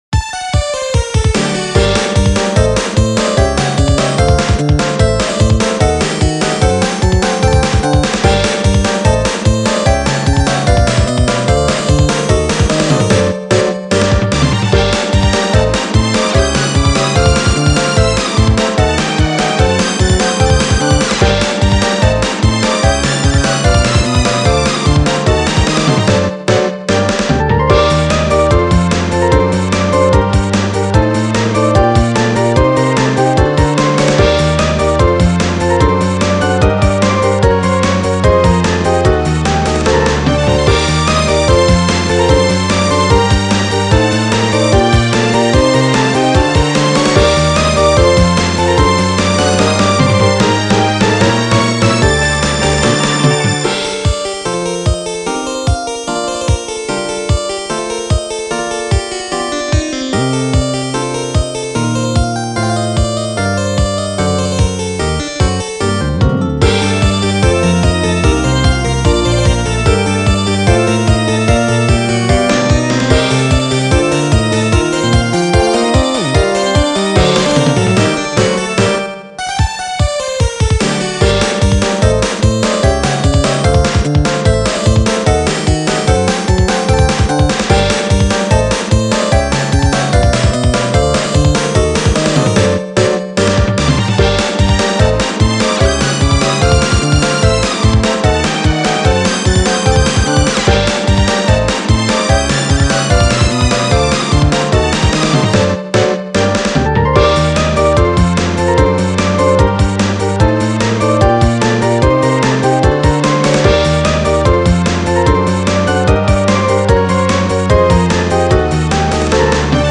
スーファミ風(16-Bit music)
ジャンルスーパーファミコン風(ゴシック、クラシック)
使用例通常戦闘曲、シリアスな戦闘
BPM１４８
使用楽器16-Bit音源(ヴァイオリン、ピアノ、チェンバロ、オルガン、アコースティックギター)